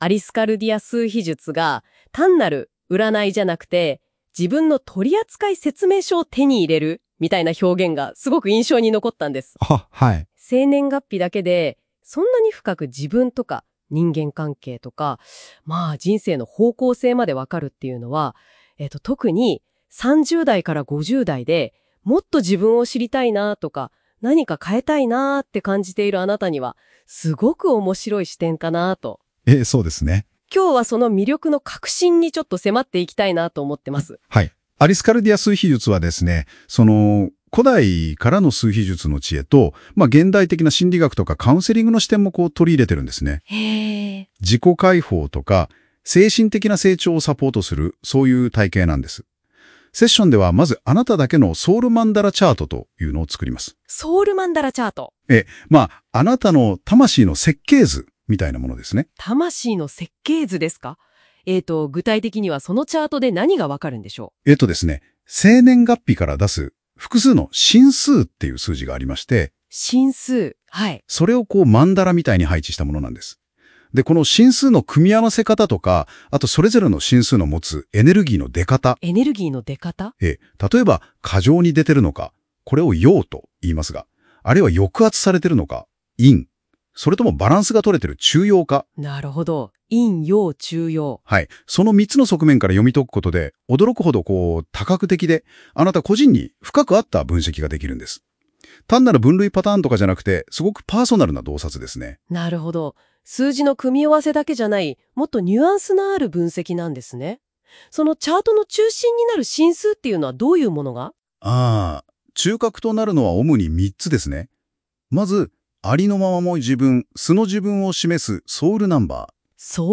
アリスカルディア数秘術のセッションについて、AIに音声（ポッドキャスト風）で説明してもらいました。